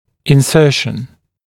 [ɪn’sɜːʃn][ин’сё:шн]введение, установка (внутрь ч.-л.); прикрепление (мышцы)